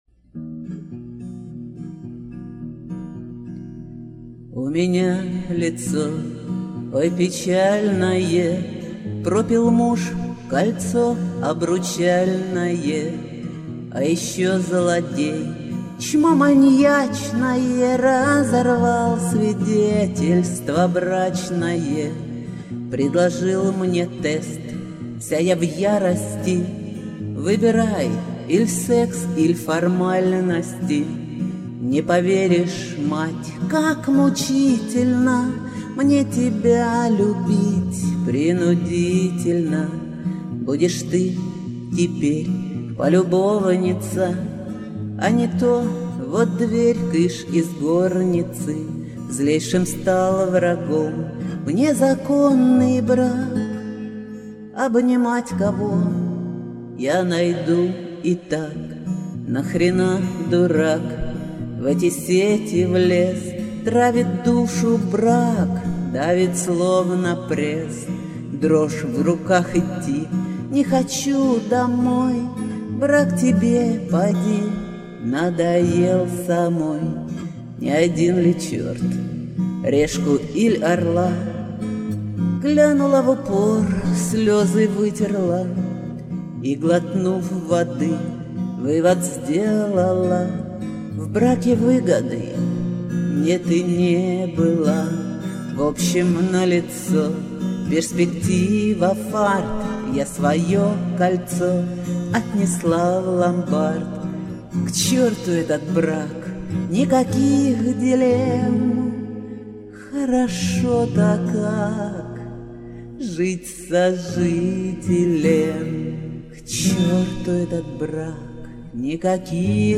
Шансон
Записала их по-памяти в домашних условиях.